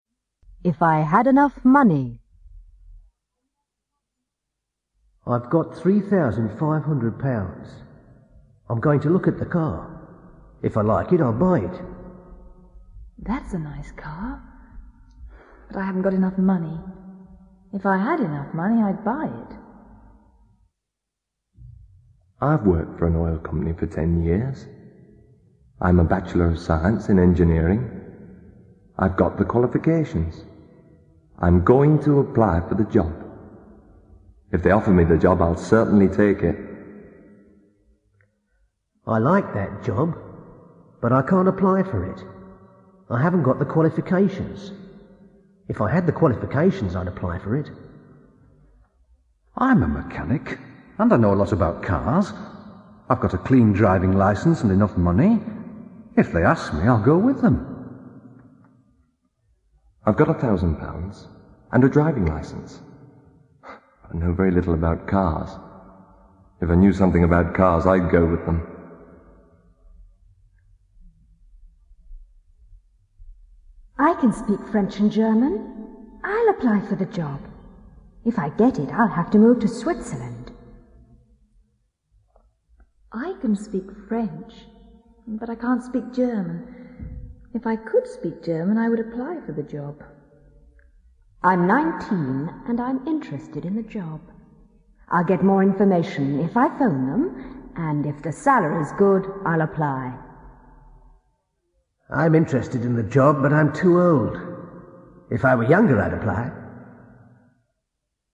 English conversation